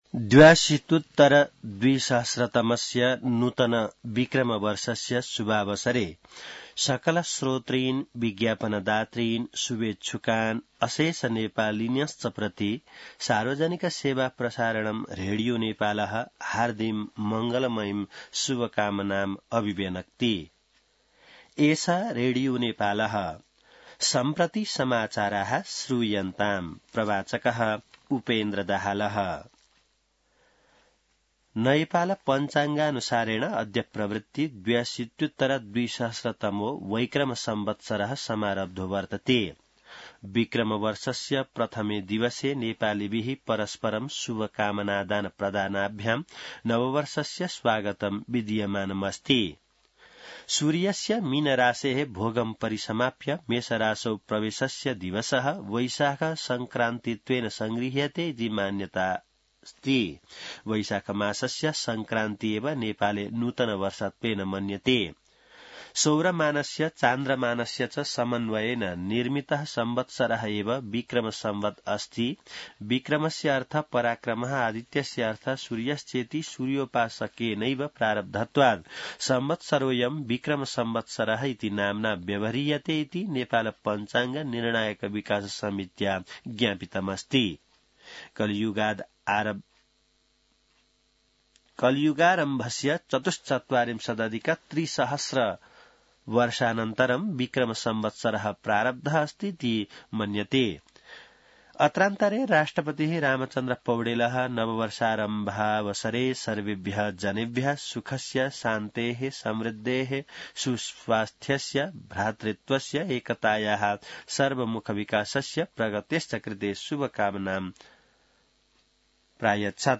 An online outlet of Nepal's national radio broadcaster
संस्कृत समाचार : १ वैशाख , २०८२